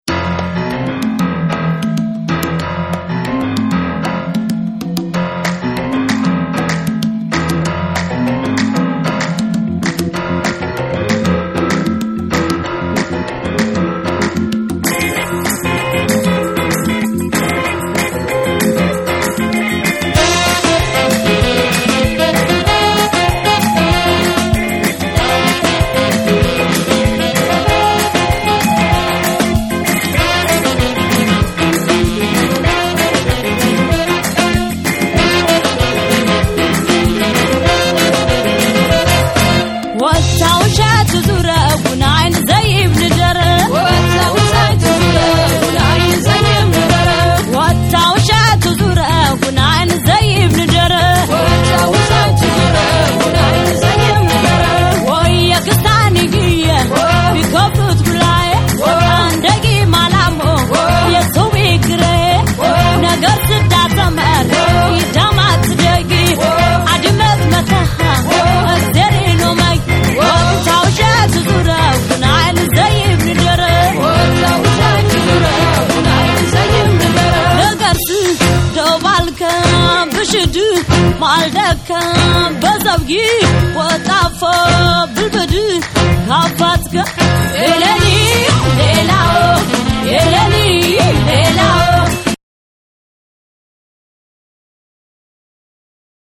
世界中に拡がったエチオ・ポップ＆ダンス・グルーヴを集めた2枚組コンピレーション。
エチオピア音楽の持っているメロディーの美しさ、叙情性にスポットを当て、エチオ・ジャズまでを幅広く収録！
WORLD / NEW RELEASE / CD